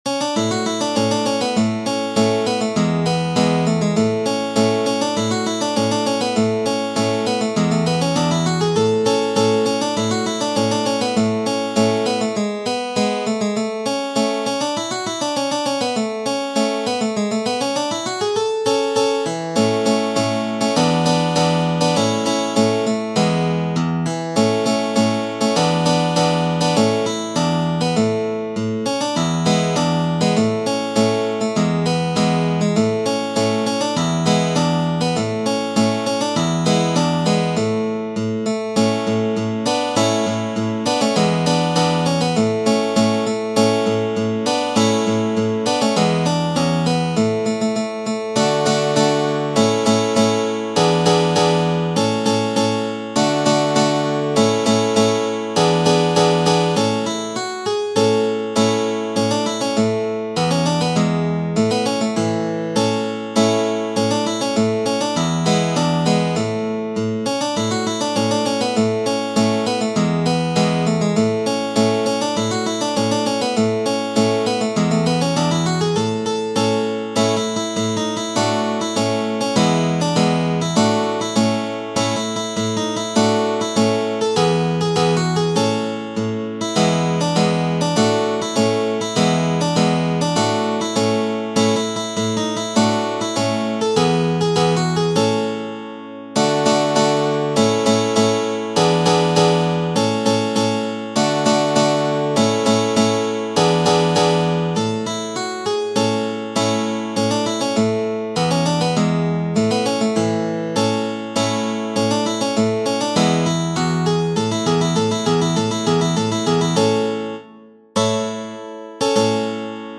In effetti, la sua musica è scorrevole e piacevolissima e ve la consiglio per esercitazioni senza soverchie difficoltà.